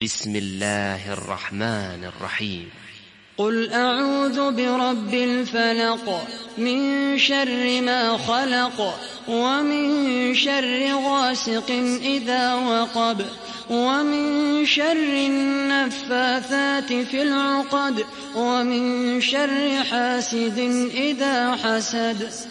تحميل سورة الفلق mp3 بصوت خالد القحطاني برواية حفص عن عاصم, تحميل استماع القرآن الكريم على الجوال mp3 كاملا بروابط مباشرة وسريعة